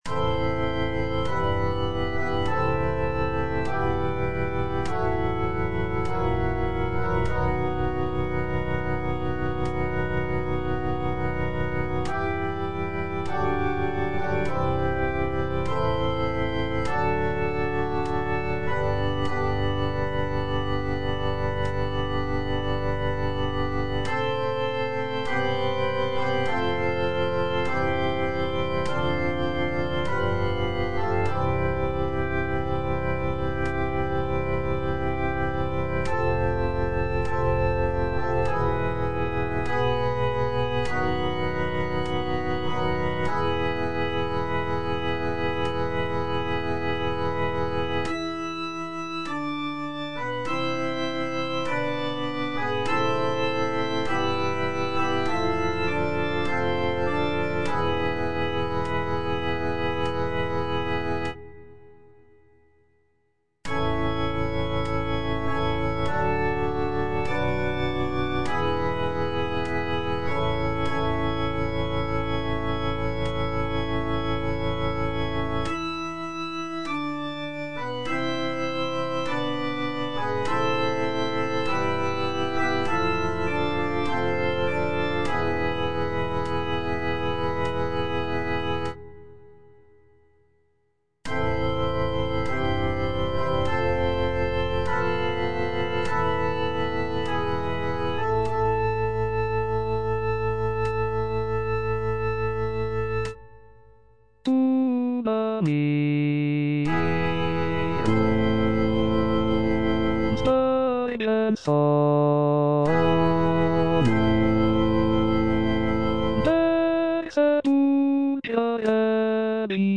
F. VON SUPPÈ - MISSA PRO DEFUNCTIS/REQUIEM Tuba mirum (tenor I) (Voice with metronome) Ads stop: auto-stop